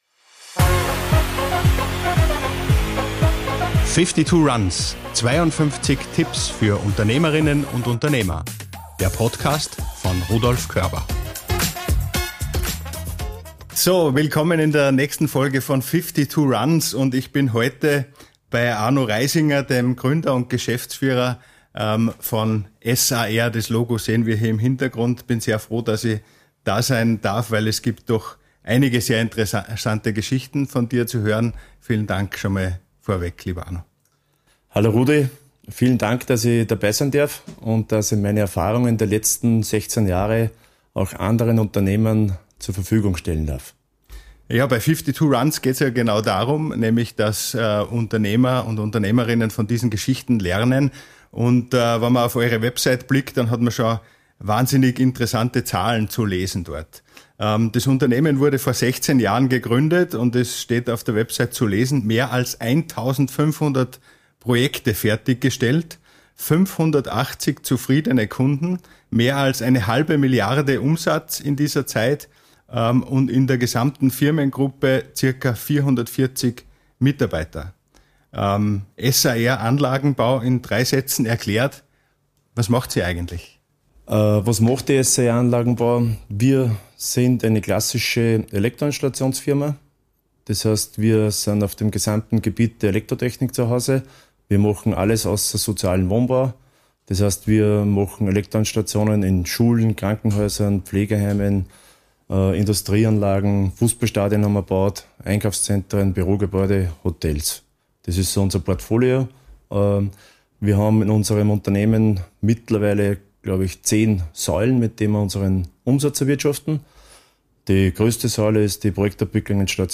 Ein inspirierendes Gespräch über Leadership, Teamgeist und die Kraft, jede Hürde zu meistern.